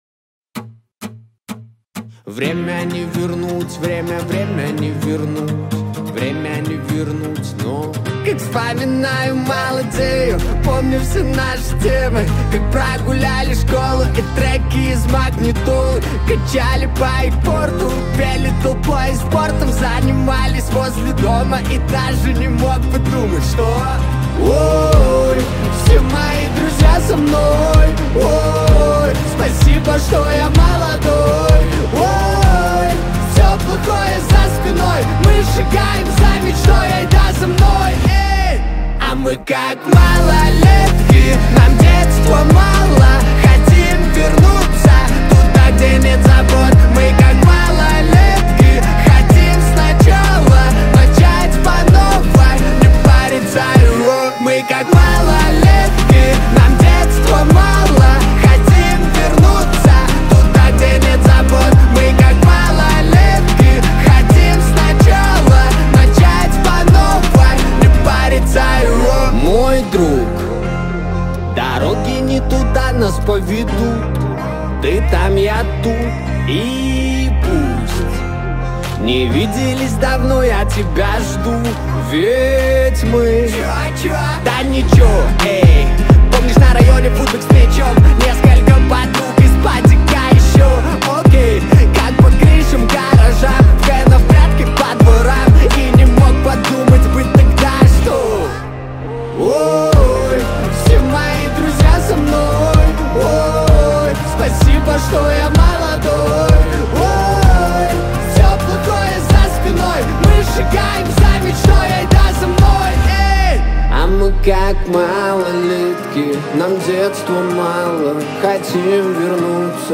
• Категория: Русские песни